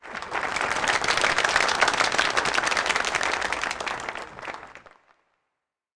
Applause Sound Effect
Download a high-quality applause sound effect.
applause-8.mp3